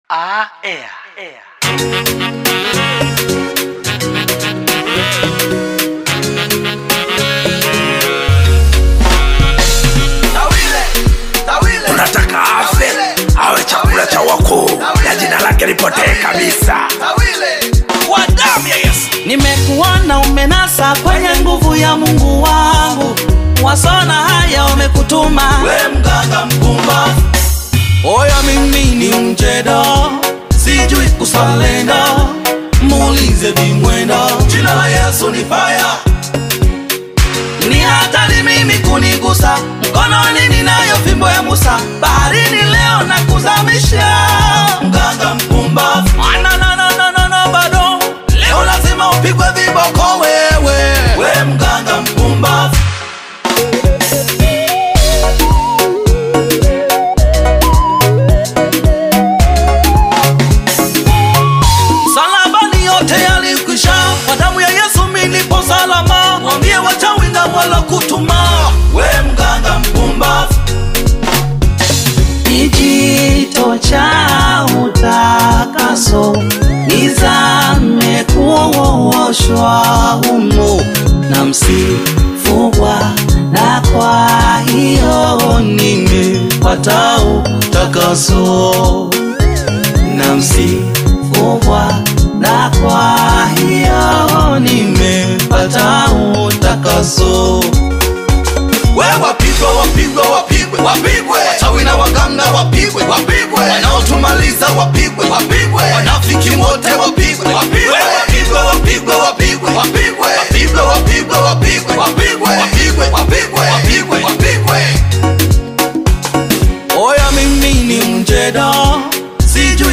Gospel music track
Tanzanian Gospel artist, singer, and songwriter